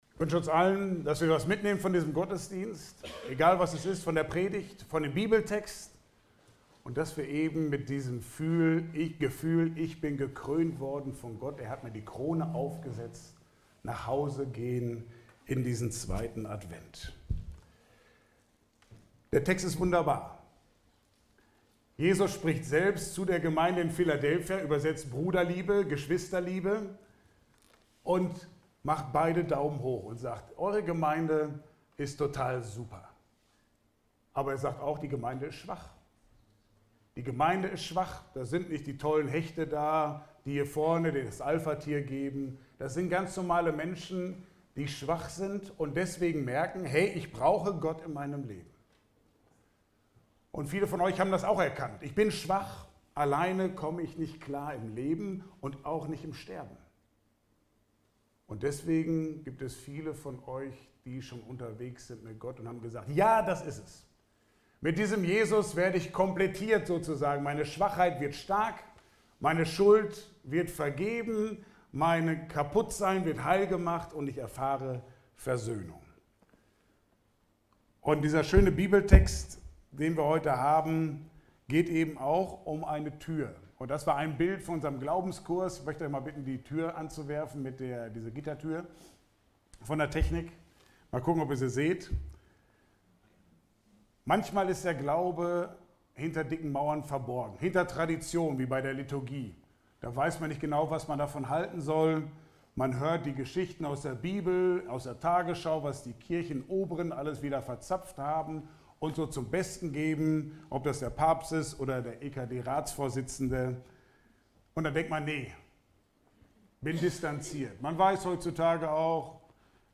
Passage: Offenbarung 3, 7-13 Dienstart: Gottesdienst « Wenn Du heute vor Gott stehen würdest